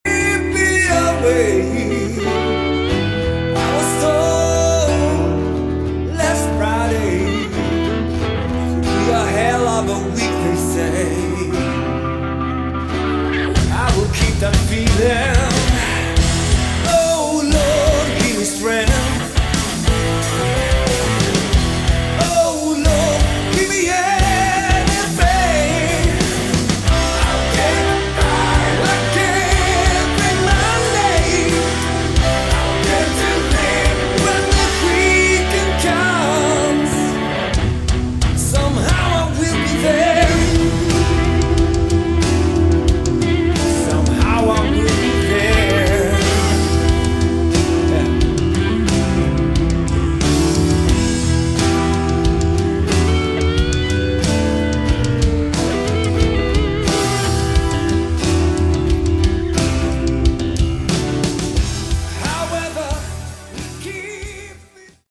Category: Melodic Hard Rock
vocals, guitars
bass
keyboards
drums